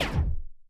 poly_explosion_small.wav